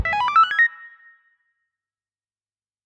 pling.wav